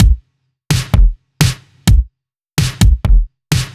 Index of /musicradar/french-house-chillout-samples/128bpm/Beats
FHC_BeatA_128-03_KickSnare.wav